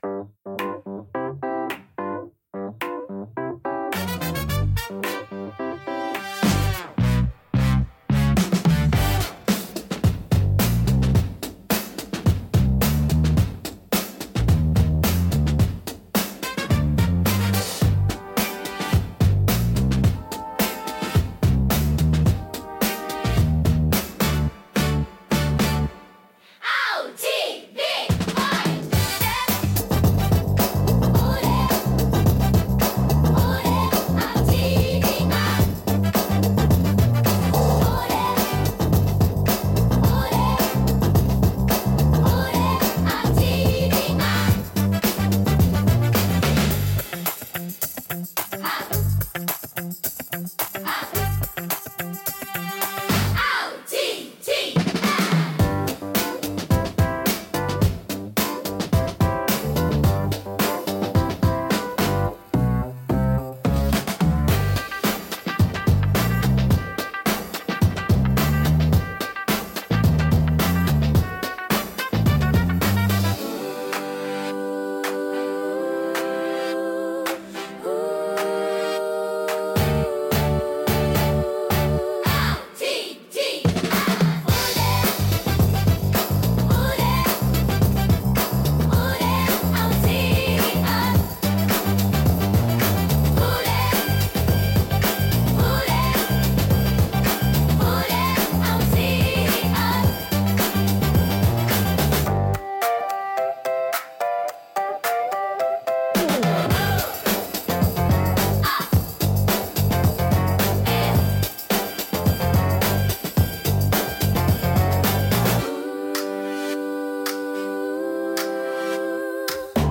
親しみやすいサウンドとポップな雰囲気、明るく感情的な楽曲が多いです。
心に残るハーモニーと温かさが魅力のジャンルです。